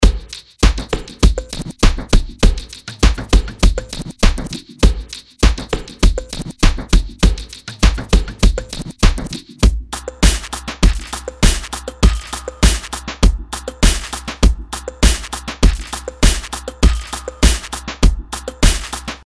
Es geht aber auch satt und trocken: Preset „Appointment Fixed 2“: